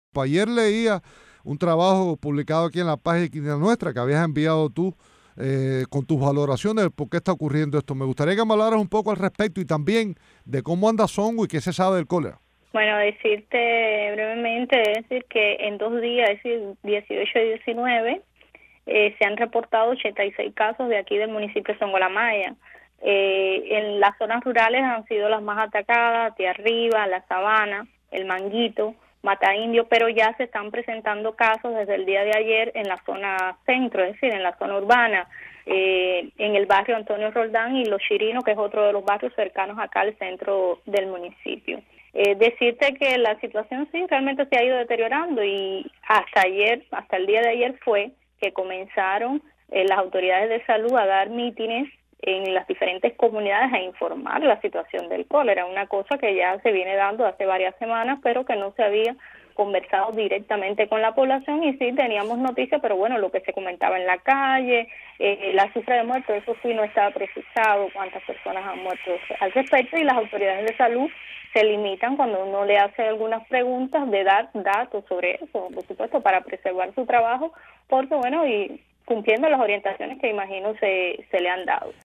en el programa radial Cuba al día